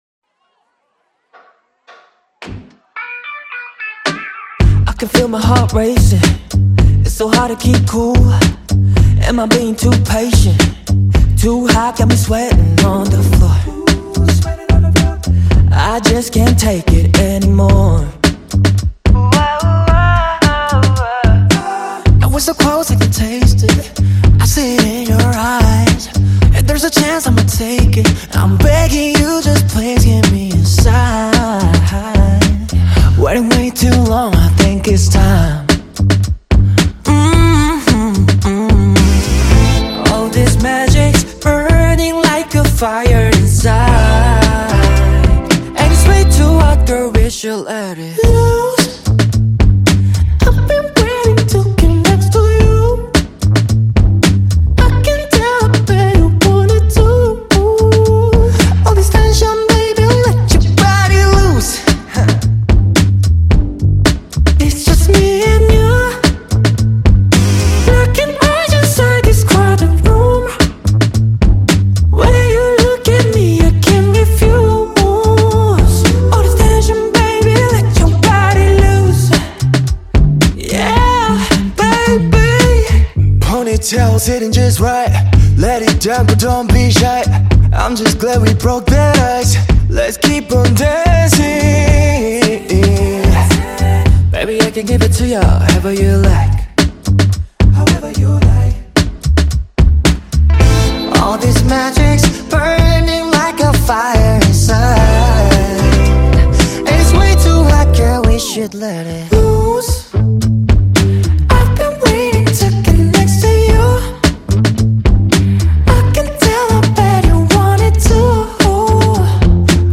KPop Song
Label Dance